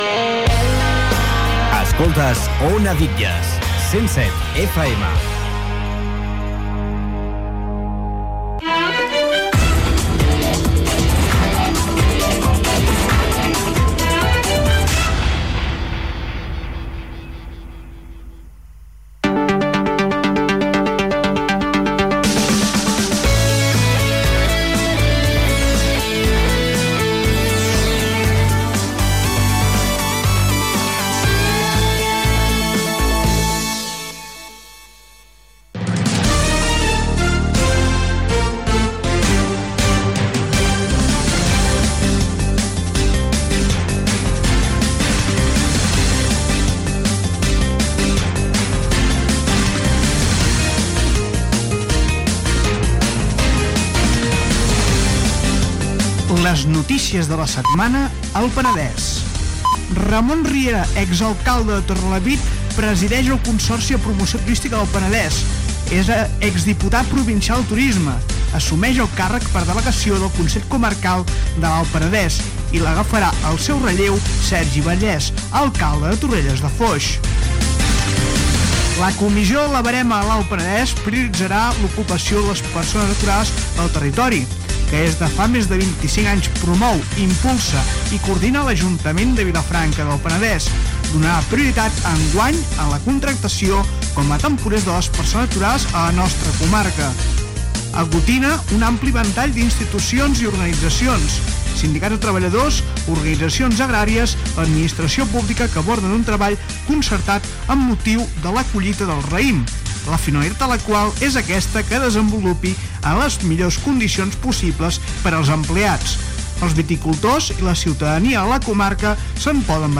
Indicatiu de la ràdio, publicitat, indicatiu, promoció del programa "Coplejant", indicatiu Gènere radiofònic Informatiu